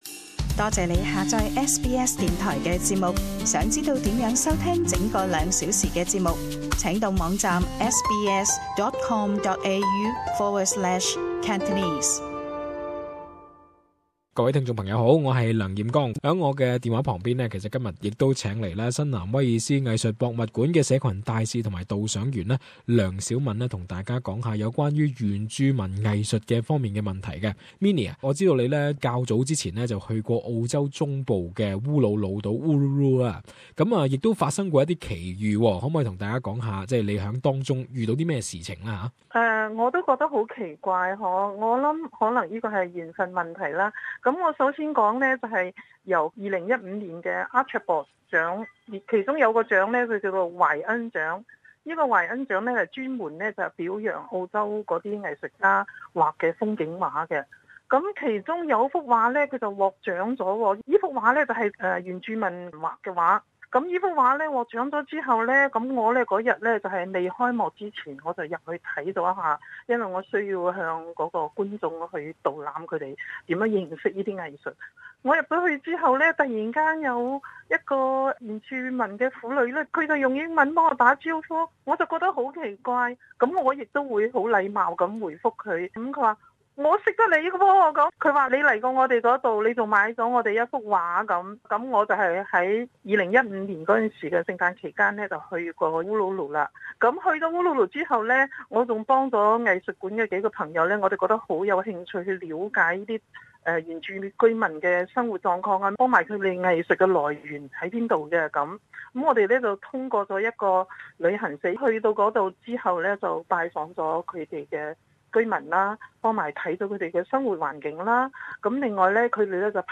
【社区专访】恋上原住民艺术